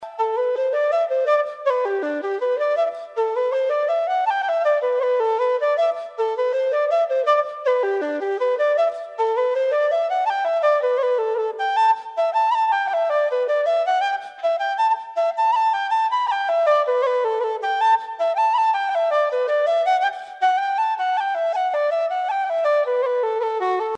Traditional Irish Music - learning resources
Simple Audio -- Piano (mp3)